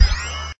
CBHQ_CFO_magnet_on.ogg